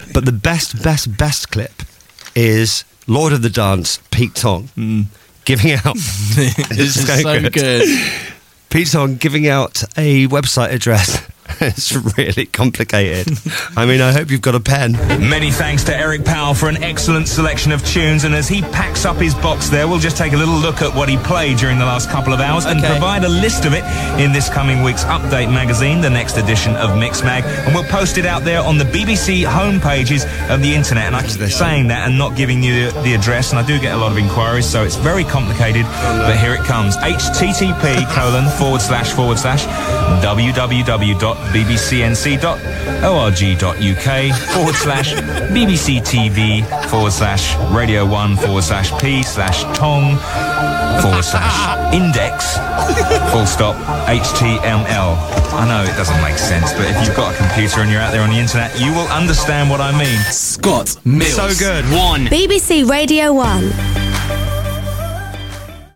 Pete Tong reads out the Radio 1 website address for the first time in a clip dug out by Scott Mills.